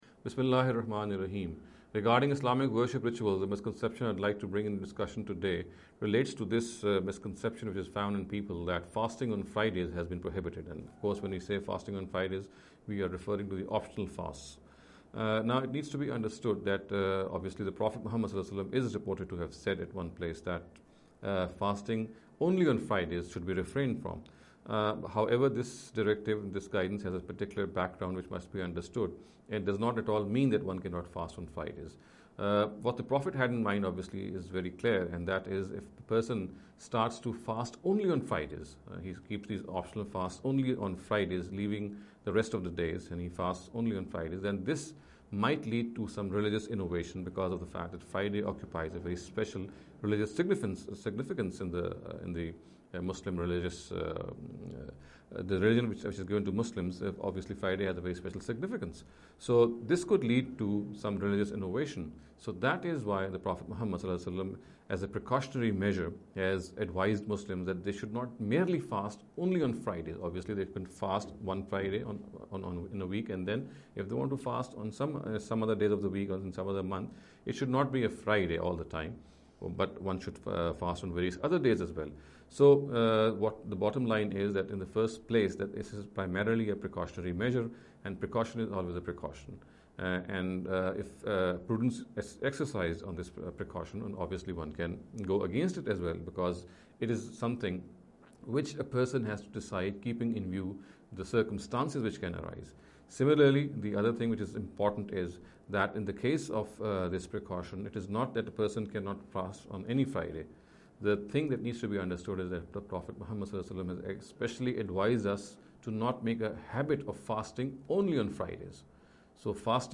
This lecture series will deal with some misconception regarding the Islamic Worship Ritual.